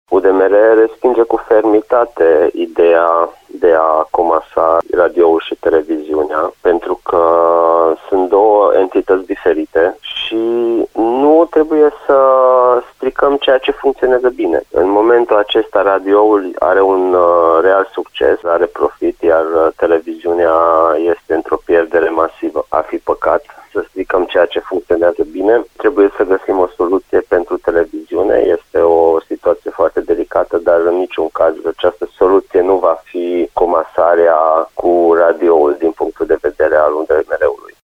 Deputatul UDMR de Timiş, Molnar Zsolt, spune că subiectul nu poate fi luat în discuţie în contextul gravelor probleme financiare cu care se confruntă în acest moment televiziunea: